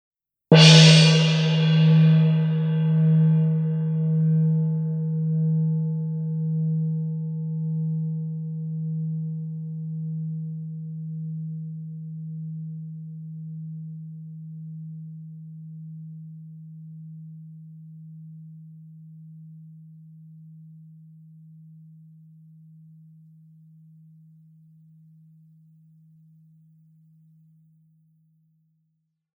PLAYTECH 18-inch gong.